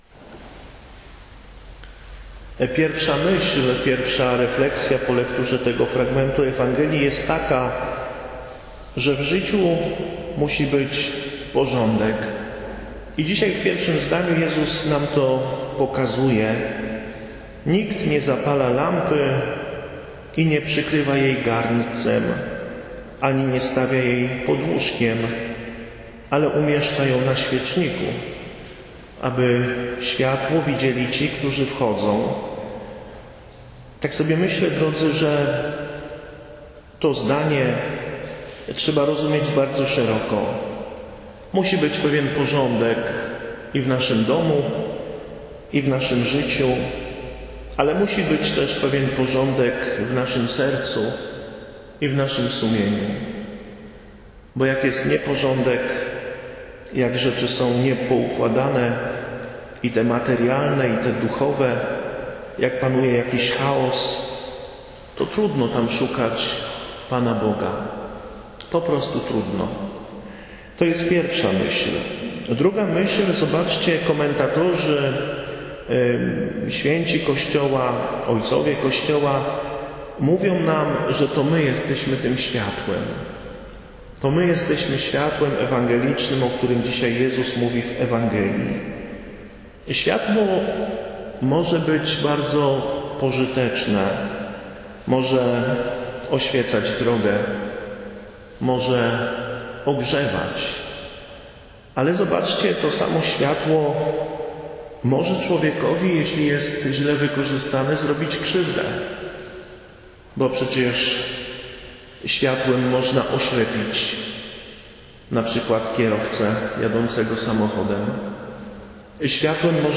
Audio - kazania w kościele